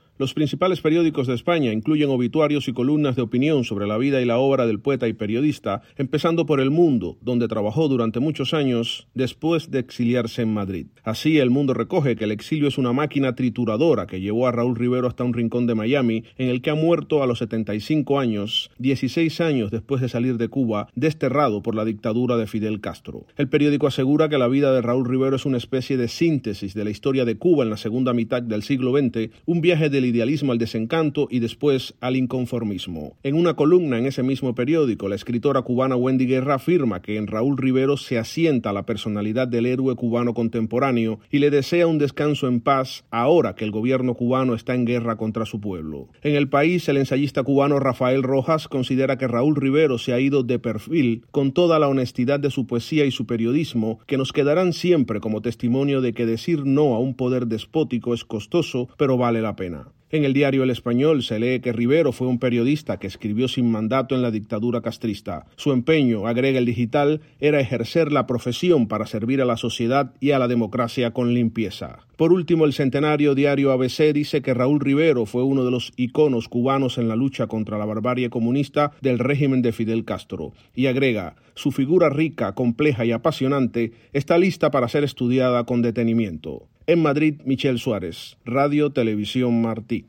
desde Madrid